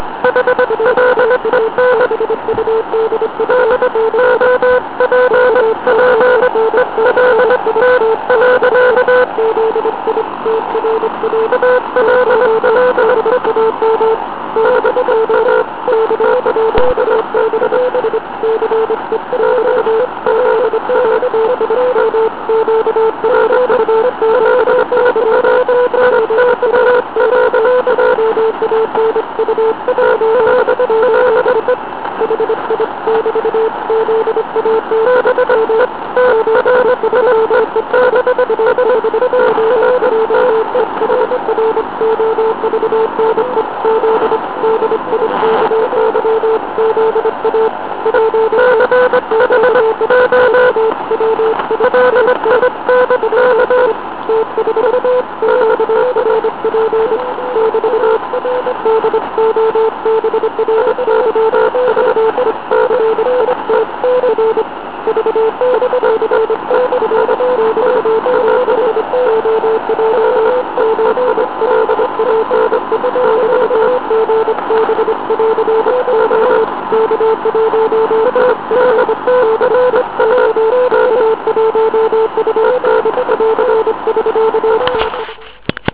Včera se poprvé s tímto zázrakem objevil "on air". Protože jeho vysílaný text obsahuje "tajné milenecké doložky určené jedné amatérce v Liberci", dovolil jsem si ho zakódovat přídavným signálem tak, aby automatické dekódovače tento tajný text nerozluštily (  NWHF metoda kodování - No Whole Hog Filter ).